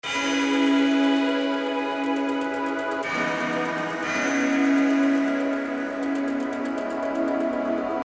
その名の通り、まるでテープに一度録音したかのような温かみのあるLo-Fiサウンドが特徴です。